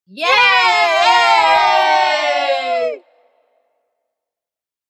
Happy-family-cheering-sound-effect.mp3